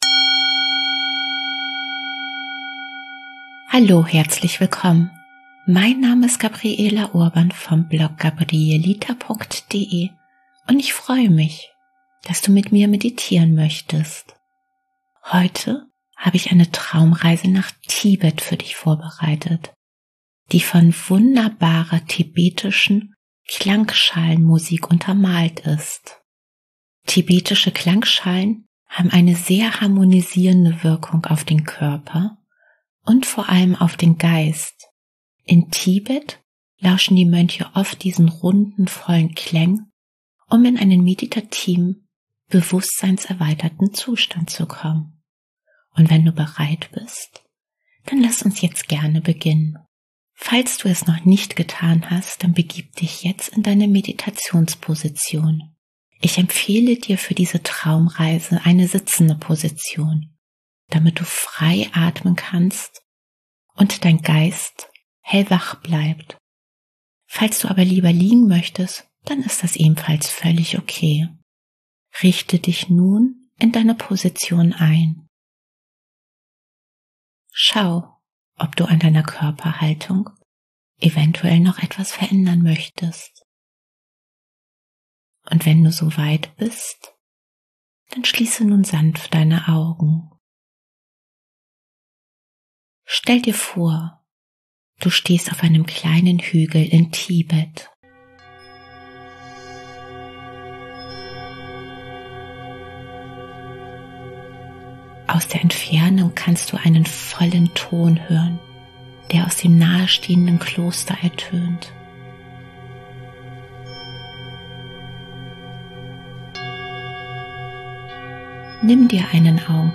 Heute habe ich eine Traumreise nach Tibet für dich vorbereitet, die von wunderbaren tibetischen Klangschalen untermalt ist. Tibetische Klangschalen haben eine sehr harmonisierende Wirkung auf den Körper und vor allem auf den Geist. In Tibet lauschen die Mönche oft diesen runden, vollen Klängen, um in einen meditativen, bewusstseinserweiterten Zustand zu kommen.